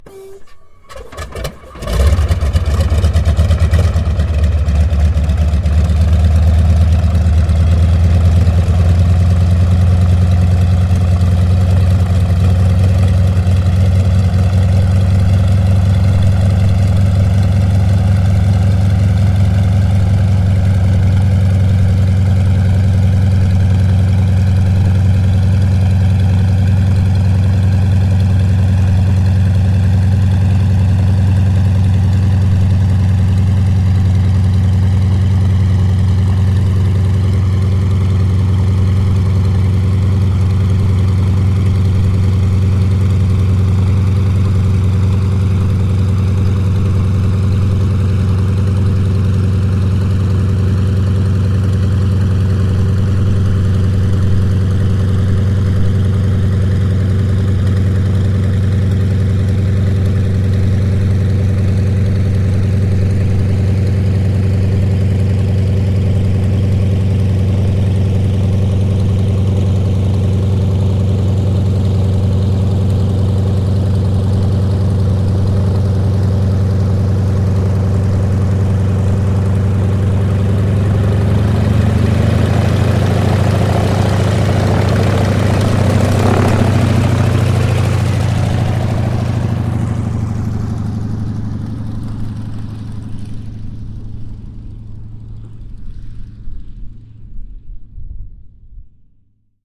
На этой странице собраны звуки военных самолетов разных типов: от рева реактивных двигателей до гула винтовых моделей.
Звук запуска двигателя ЯК-52 запись снаружи самолета